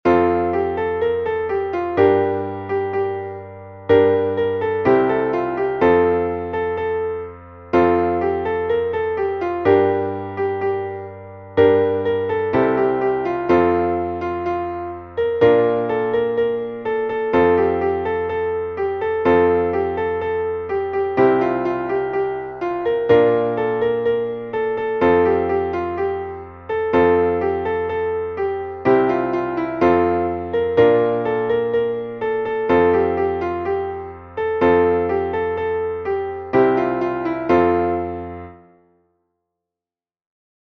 μελωδία και συγχορδίες, Fmaj